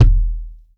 impact_deep_thud_bounce_05.wav